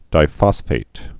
(dī-fŏsfāt)